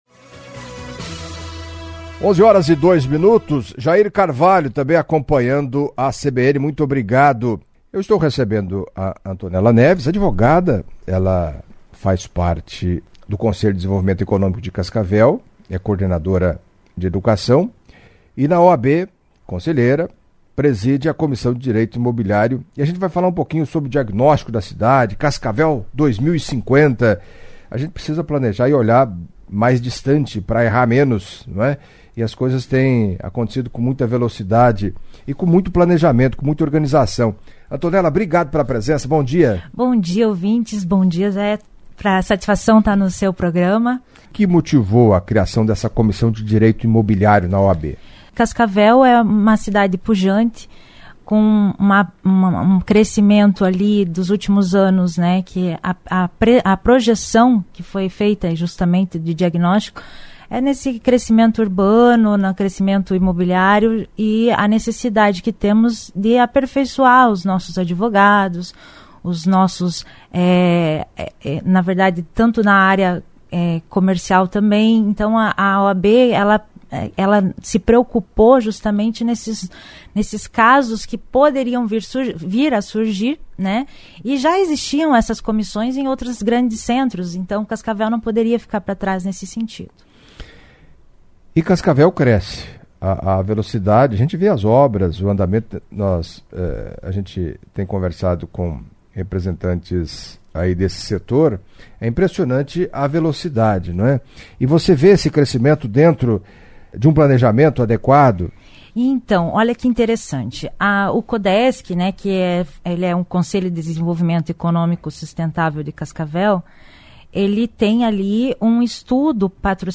Em entrevista à CBN Cascavel nesta terça-feira (25)